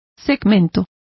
Complete with pronunciation of the translation of segment.